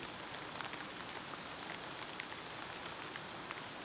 Mecocerculus hellmayri
Es el agudo del fondo
Nome em Inglês: Buff-banded Tyrannulet
Localidade ou área protegida: Reserva Natural Privada Ecoportal de Piedra
Condição: Selvagem
Certeza: Gravado Vocal